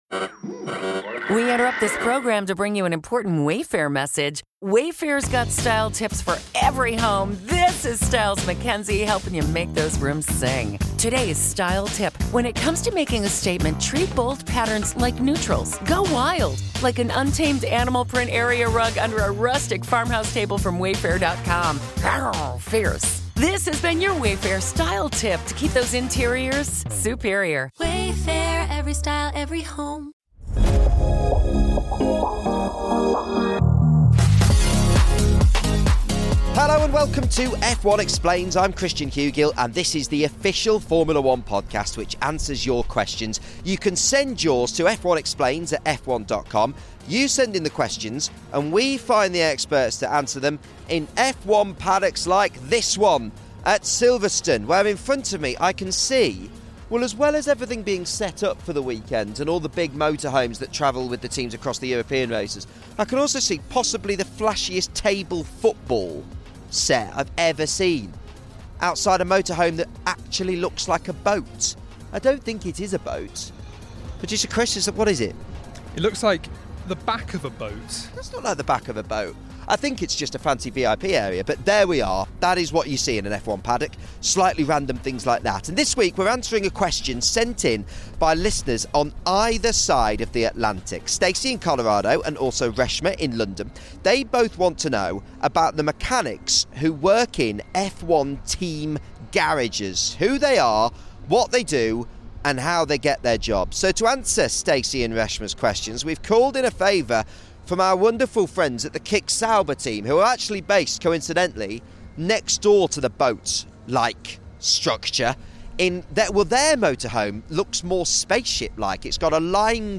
In this episode, recorded at the 2024 Canadian and British Grands Prix